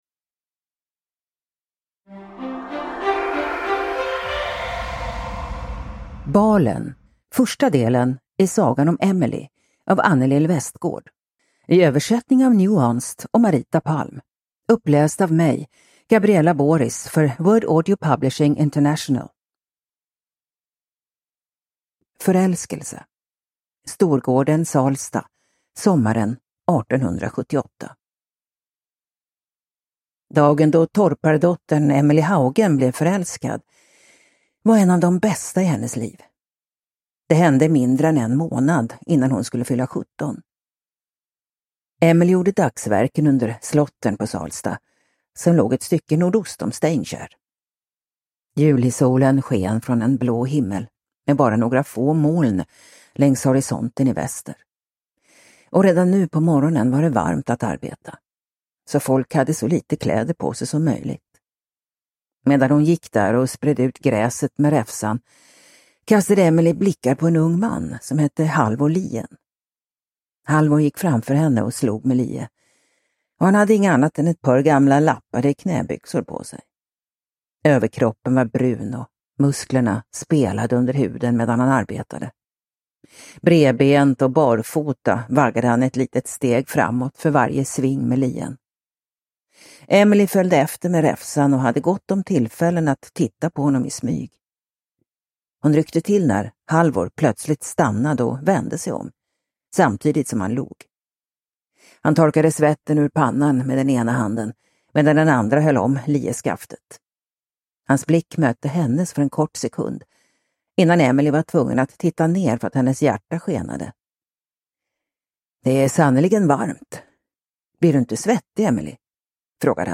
Balen – Ljudbok